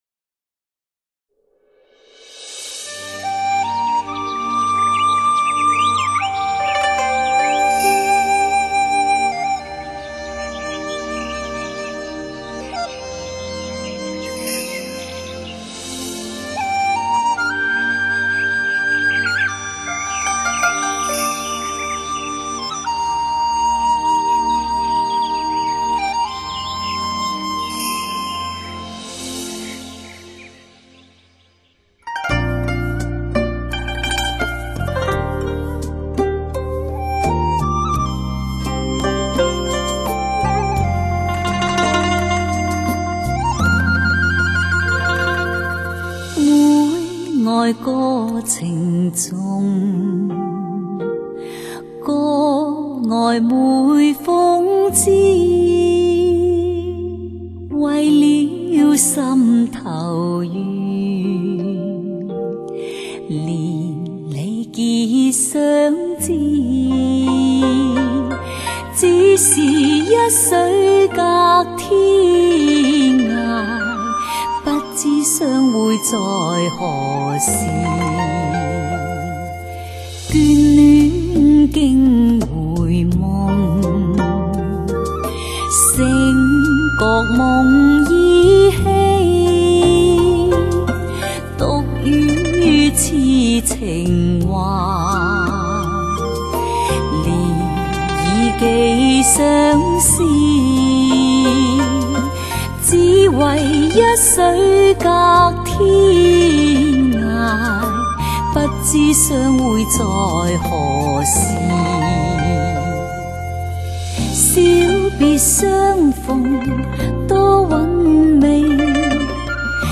【DSD】
感性流丽